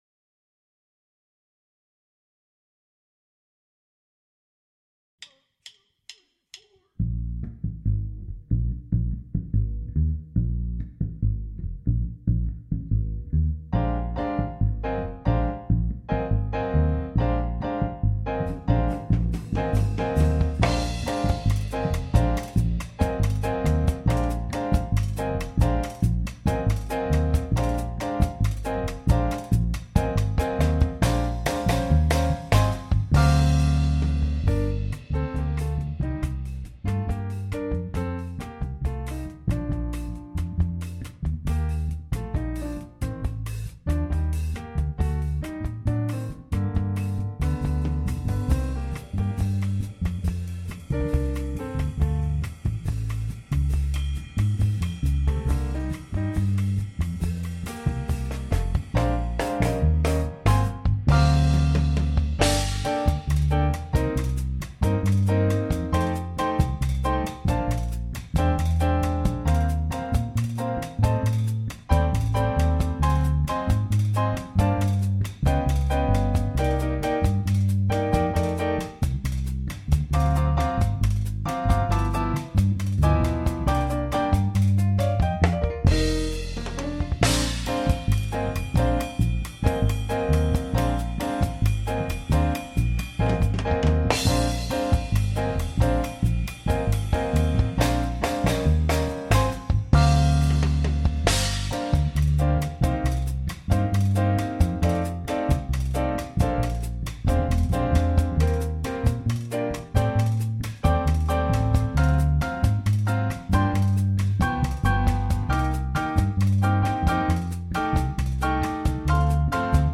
FMTTM Backing